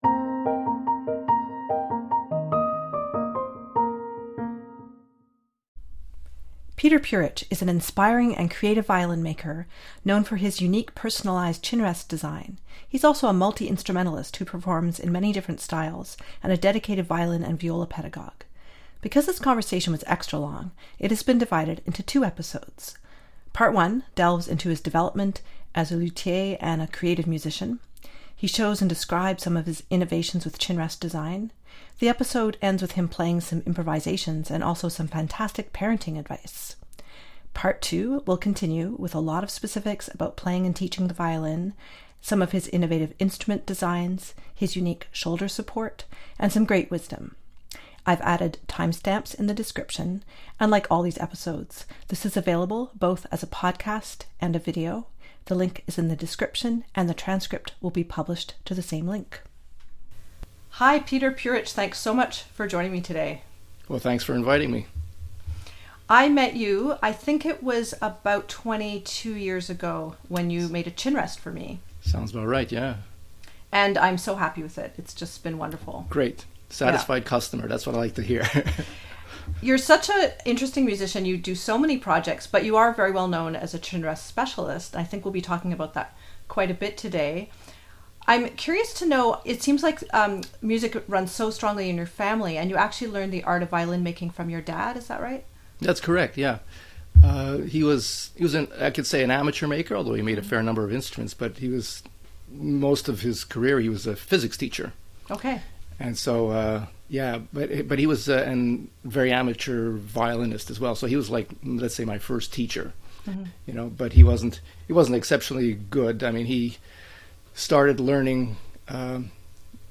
Because this conversation was extra-long it has been divided into two episodes. Part 1 delves into his development as an instrument maker and innovator, and as a creative musician. He shows and describes some of his innovations with chinrest design. The episode ends with some improvisation and some great parenting advice!